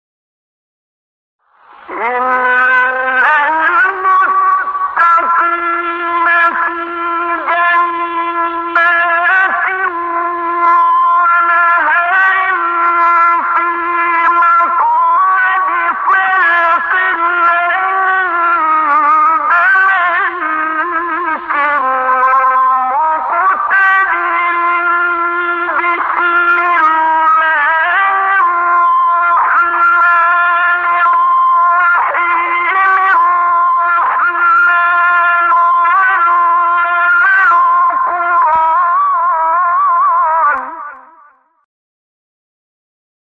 سایت-قرآن-کلام-نورانی-رست-عبدالباسط.mp3